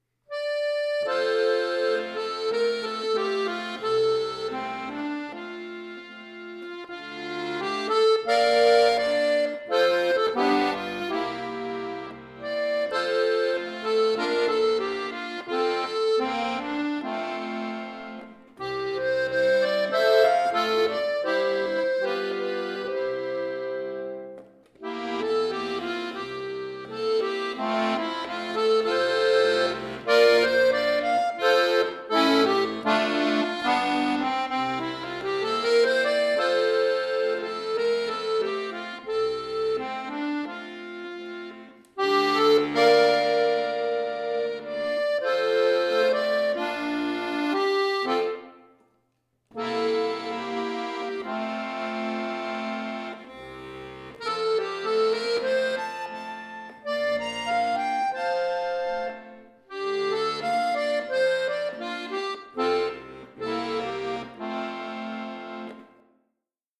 Gefühlvoller Folksong
neu arrangiert für Akkordeon solo